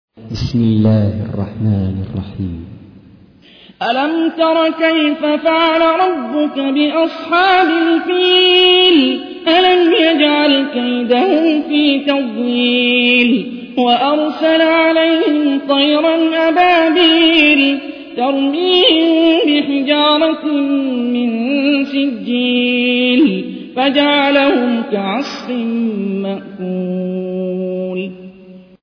تحميل : 105. سورة الفيل / القارئ هاني الرفاعي / القرآن الكريم / موقع يا حسين